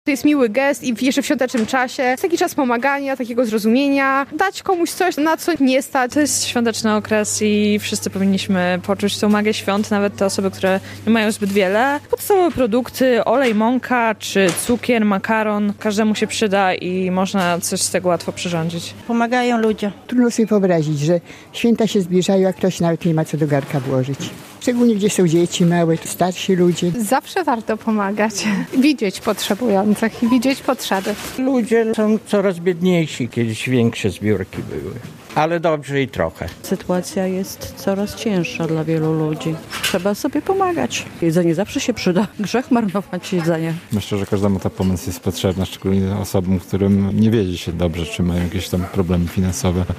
Zbiórka żywności dla najbardziej potrzebujących - relacja